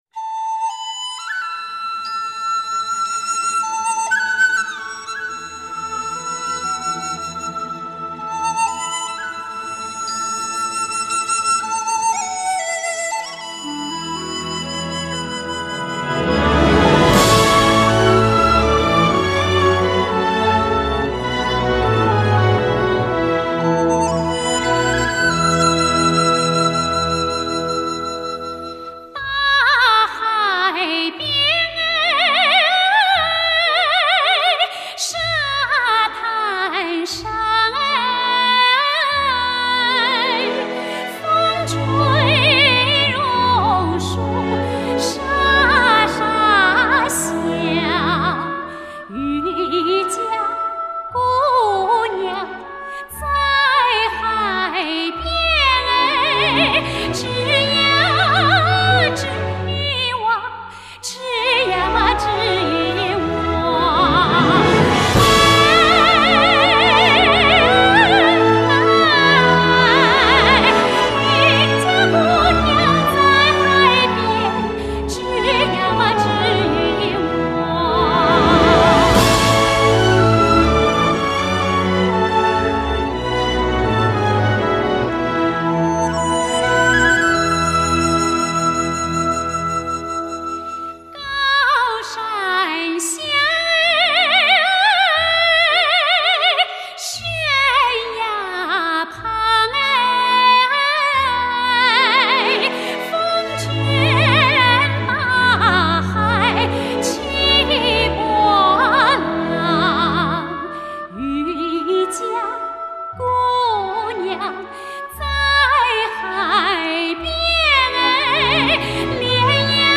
最顶级的录音，最发烧的人声!
人声之完美，弦乐之优雅，动态和保真度之高，让您真正体验最接近原声，洁净无伪高解析力的终极之声。